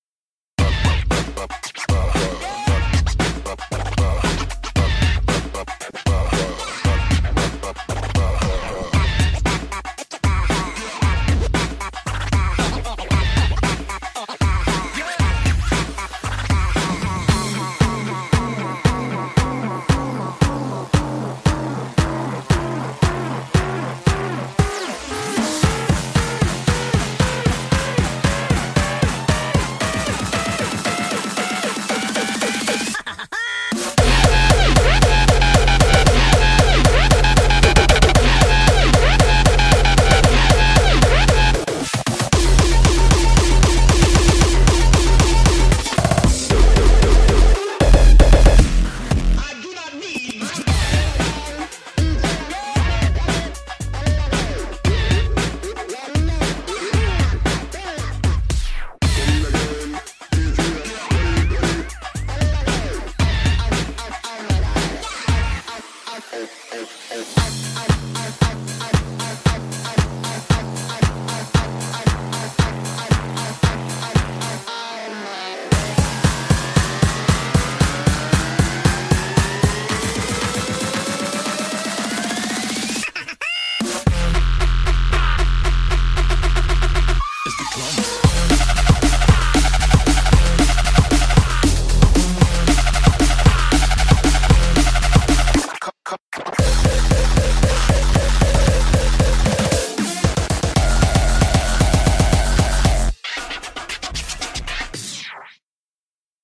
BPM115-230
Audio QualityPerfect (Low Quality)